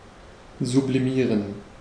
Ääntäminen
IPA : /sə.ˈblaɪm/